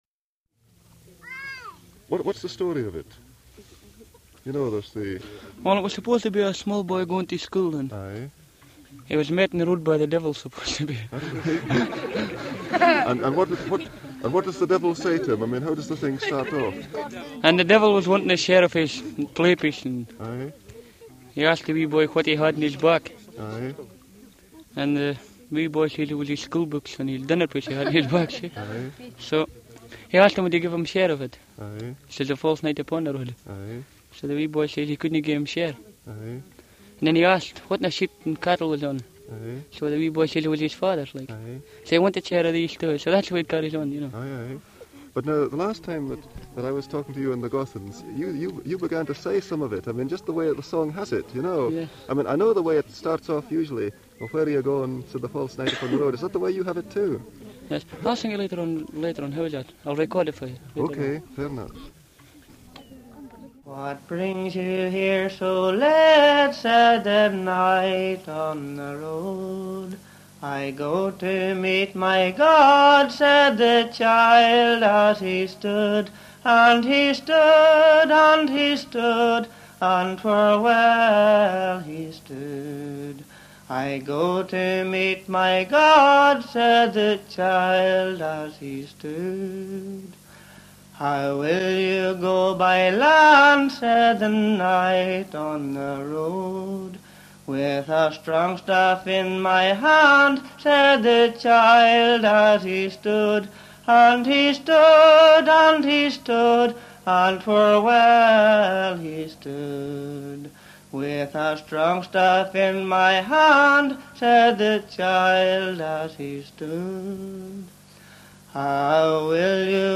BALLATE DA INGHILTERRA, SCOZIA E IRLANDA